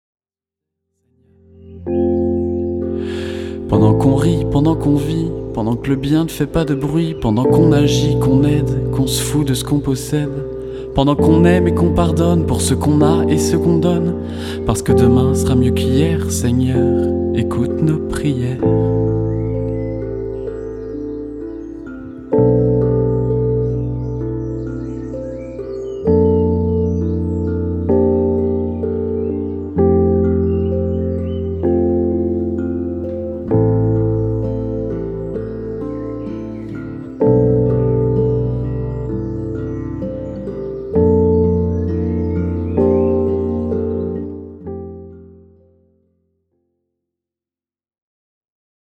poético-rock